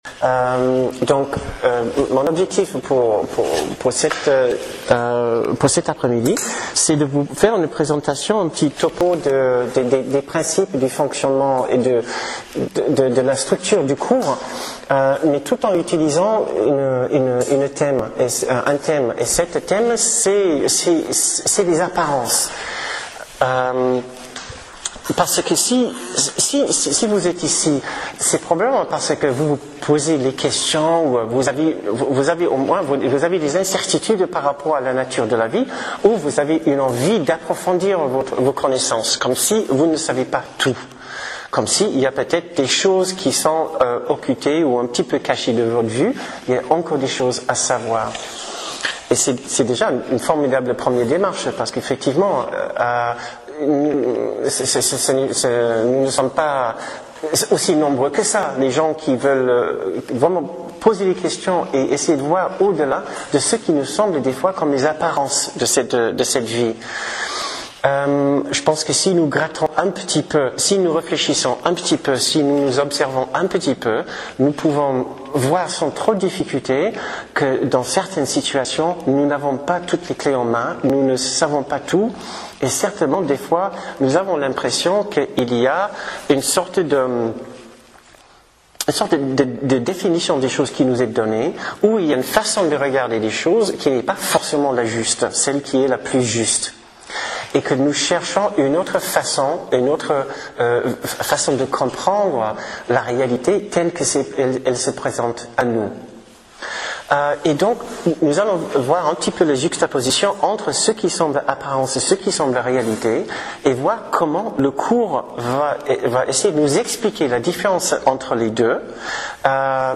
Conférences sur les thèmes spécifiques : 1. La Porte qui ne se fermera jamais 2. Comment aborder le Cours : un symbolisme puissant 3. Un esprit avec sept milliards de visages 4. Le mal-être : un ma…